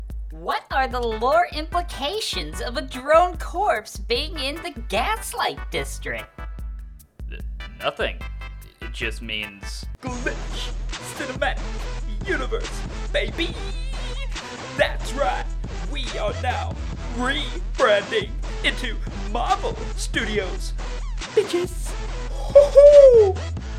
Why does Pidge sound like a dude?